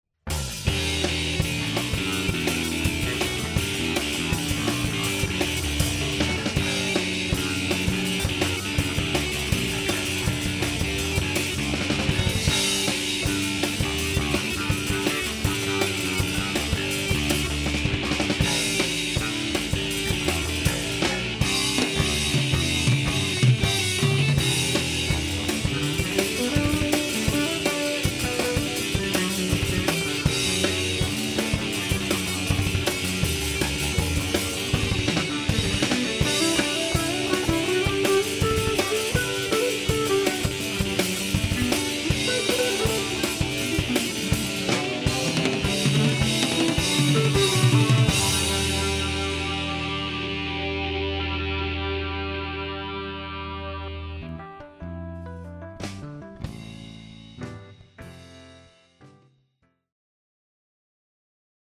keyboards
woodwinds
bass
drums